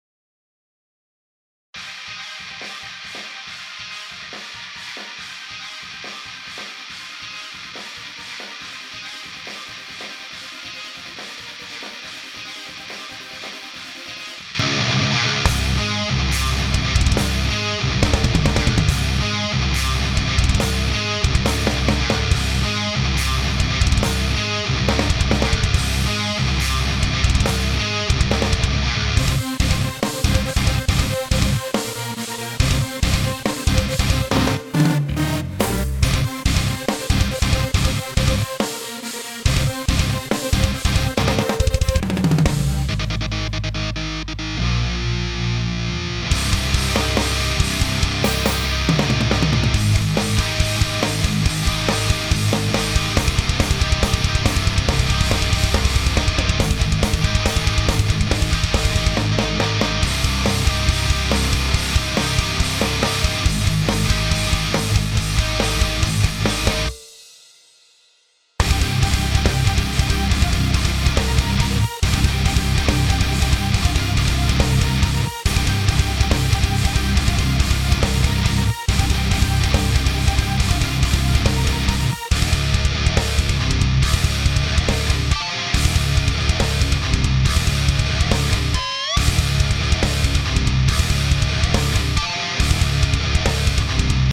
J'ai voulu avoir ce son avec beaucoup d'imprécisions dans le haut medium et l'impression d'avoir 1 tonne de gain :
->SM57 d'office et clean boost en entrée de l'ampli.
Sur la basse un bon boost sur le bas et une belle compression pour avoir un bas "tendu" et toujours sensiblement identique quelques soit les passages du morceaux qui sont très différents.
Certains noterons un léger déphasage sur les machines.